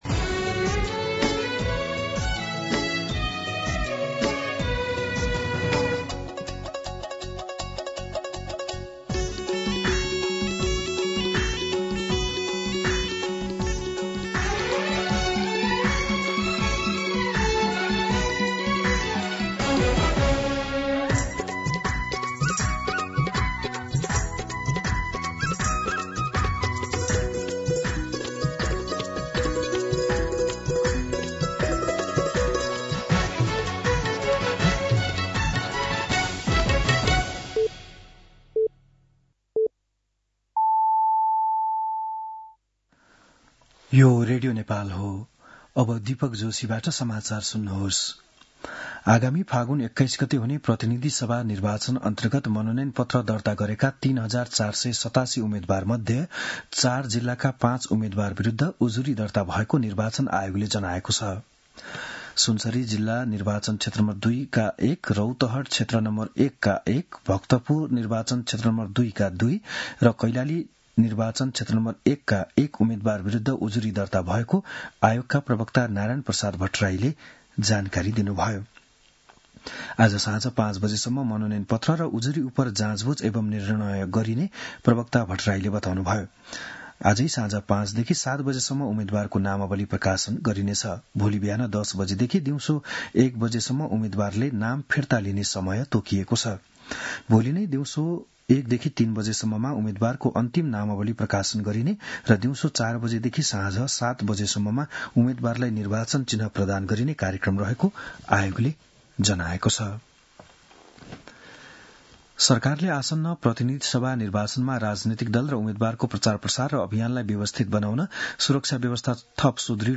बिहान ११ बजेको नेपाली समाचार : ८ माघ , २०८२
11-am-News-10-8.mp3